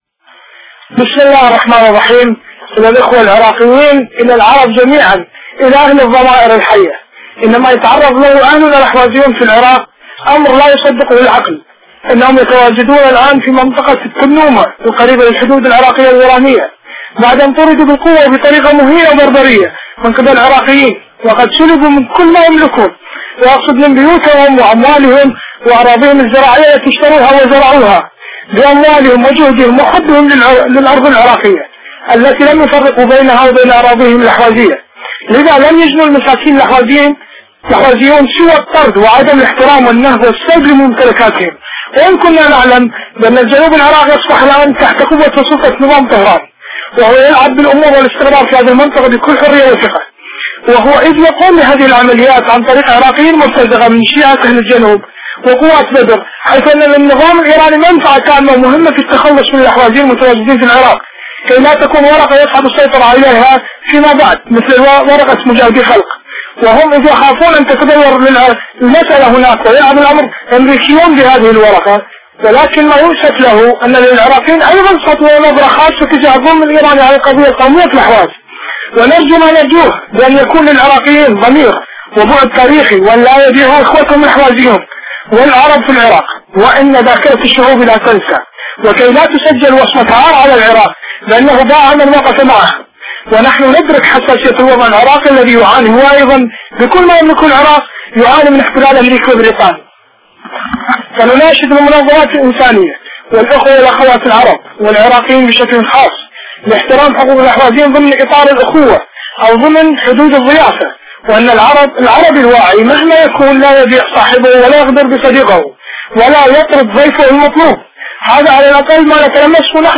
الرسالة الصوتية )